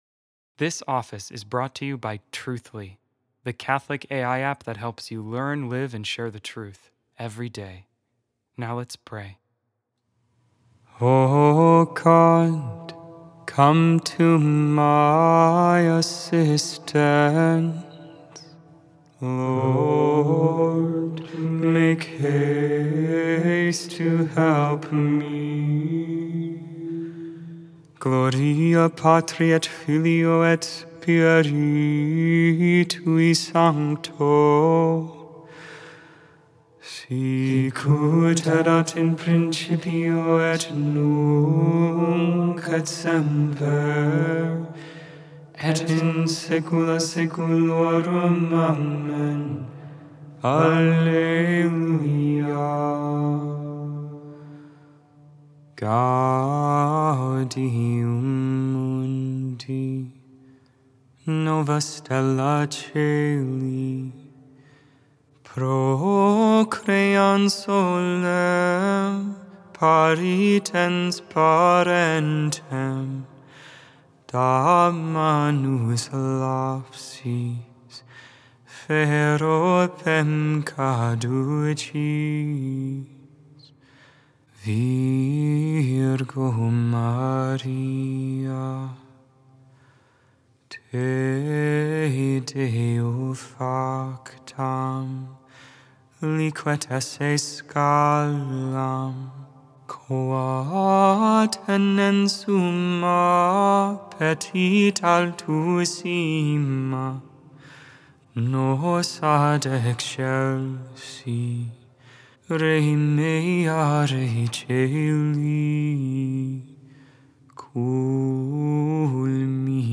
Vespers, Evening Prayer for the 19th Thursday in Ordinary Time, August 15, 2024. Solemnity of the Assumption of the Blessed Virgin Mary.